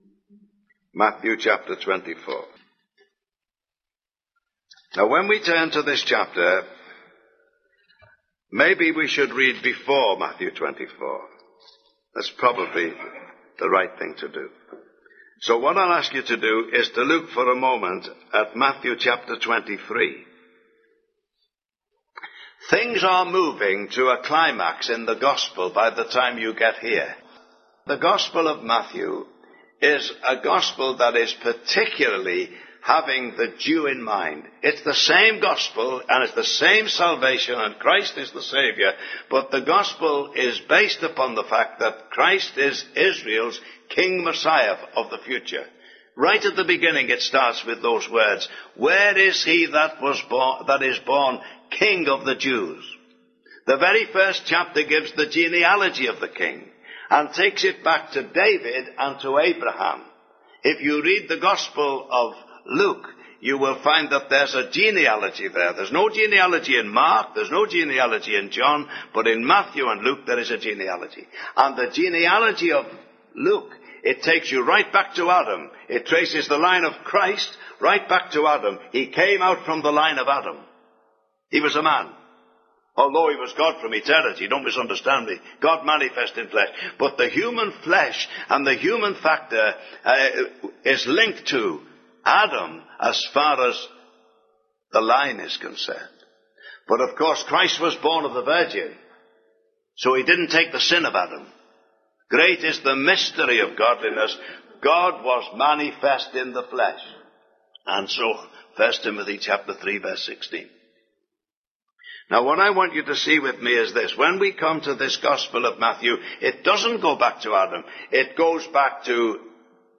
(Recorded in Ayr, Scotland)
Individual Messages on Eschatology - Various preachers